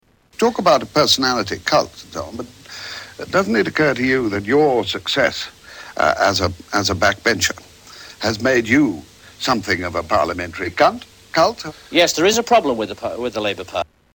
Tags: Media Freudian Slips News Newscasters Funny